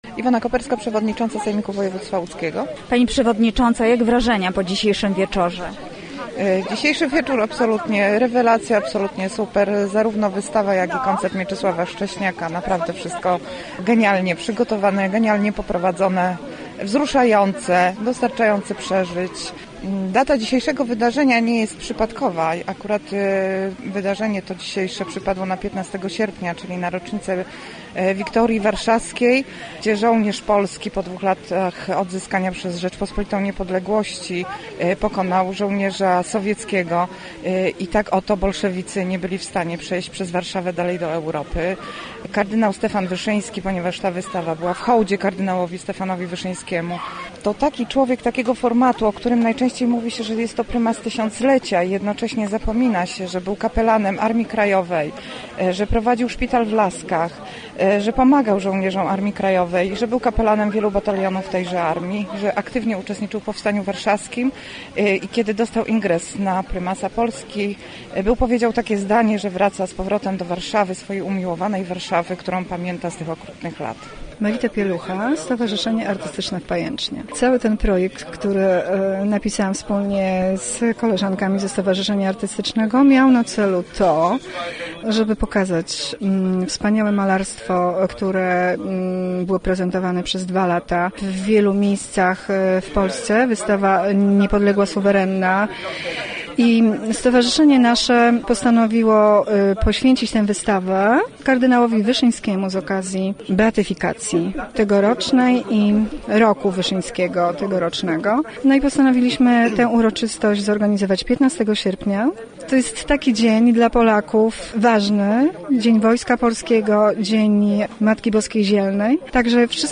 Wernisaż wystawy „Niepodległa – Suwerenna” w pałacu Męcińskich w Działoszynie
Dzisiejszy wieczór to absolutnie rewelacja – mówiła po wernisażu i koncercie Iwona Koperska, przewodnicząca Sejmiku Województwa Łódzkiego.